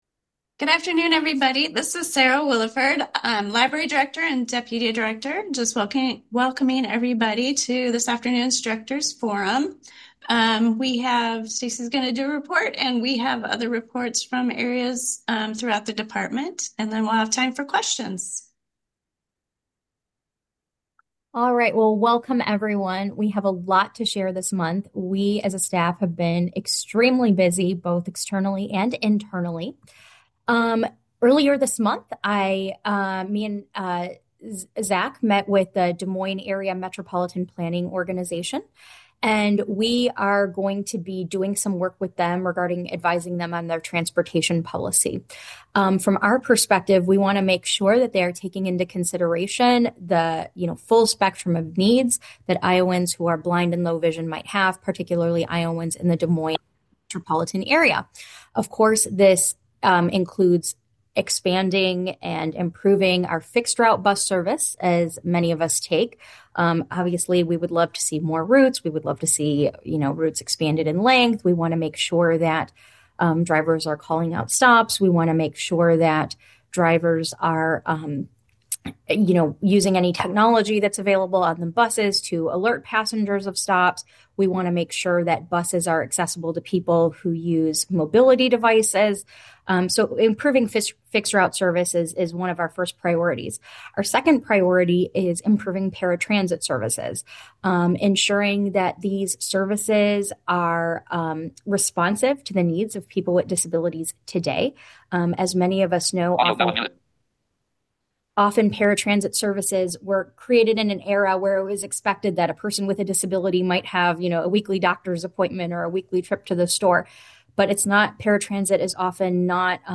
Each month the Director's Forum is held live via Zoom on the Fourth Tuesday of the month at 12:00pm. This podcast is a recording of the monthly forum.